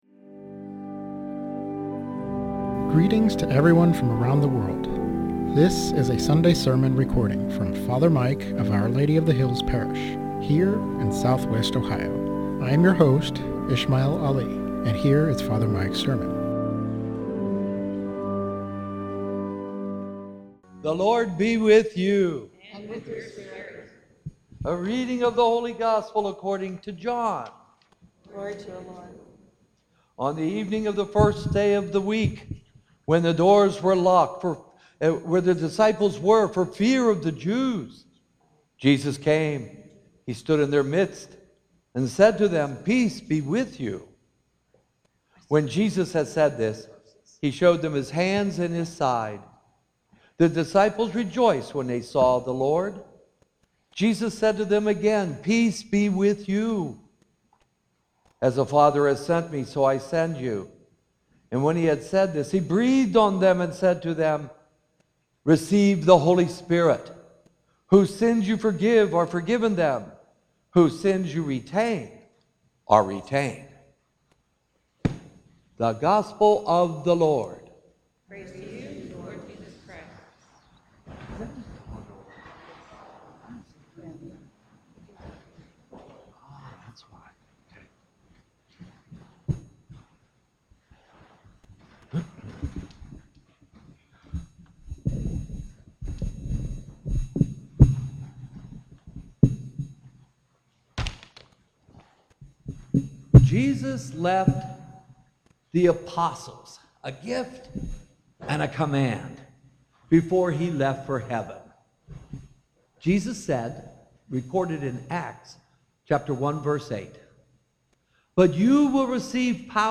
SERMON FOR PENTECOST